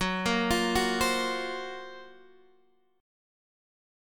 F#M7b5 chord